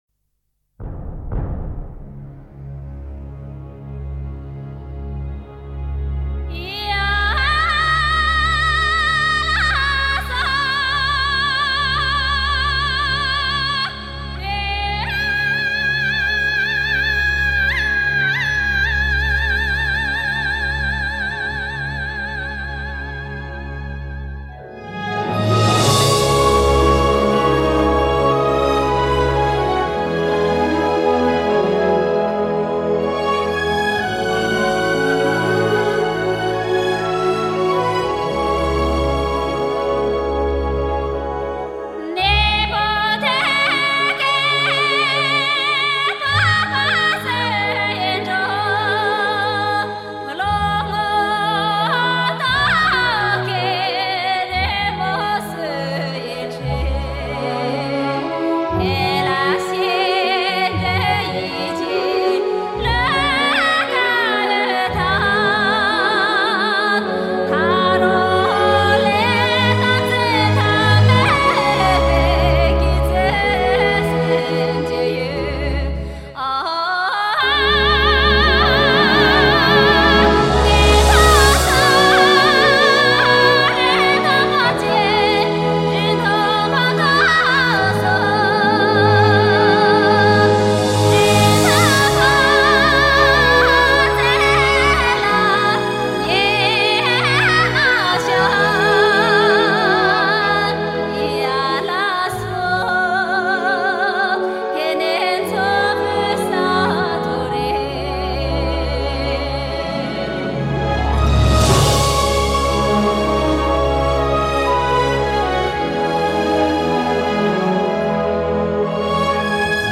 她的声音像山间的风一样自然  像高原的天一样明亮  像冰峰的雪莲一样纯净
试听的是低品质